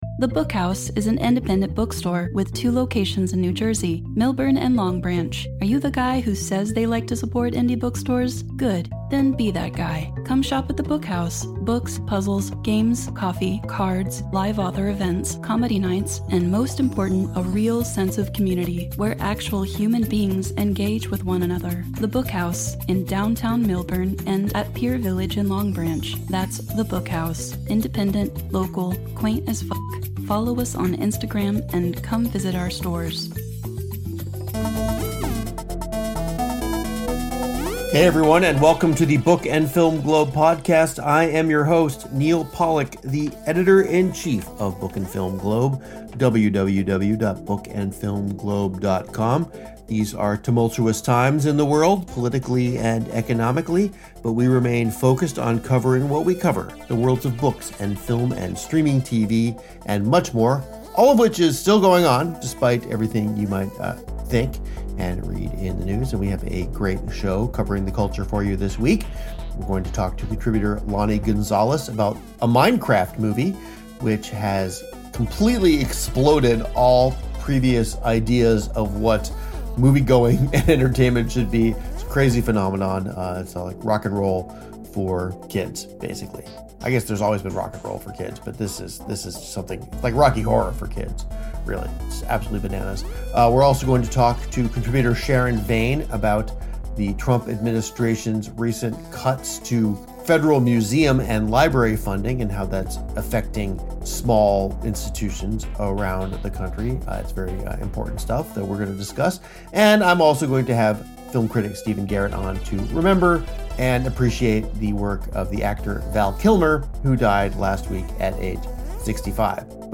Entertaining, enlightening chat about books, film, streaming TV, and more